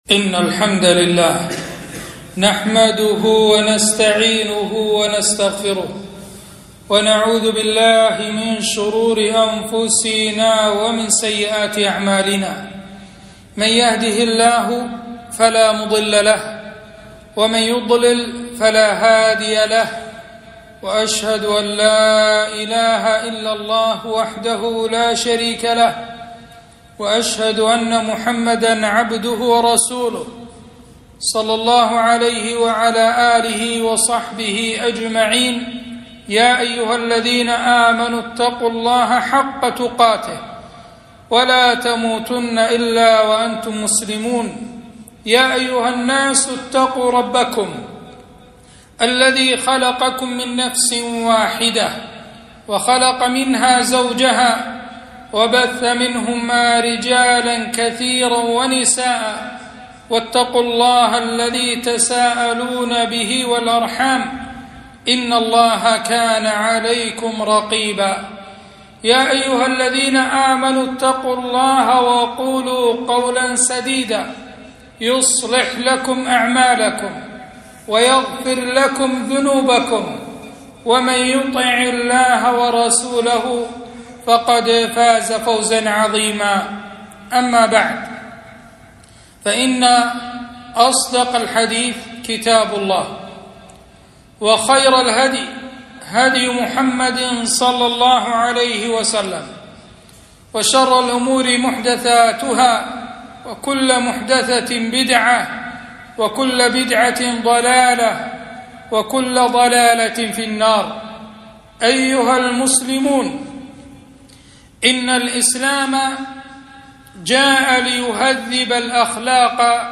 خطبة - مراعاة المشاعر